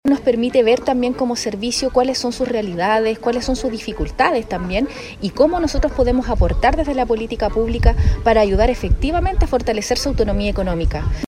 Hasta Pichasca, en la comuna de Río Hurtado, llegaron mujeres de Punitaqui, Combarbalá, Monte Patria y Ovalle, quienes formaron parte de un valioso encuentro provincial, impulsado desde el Servicio Nacional de la Mujer y la Equidad de Género (SernamEG) junto a los municipios ejecutores de la provincia.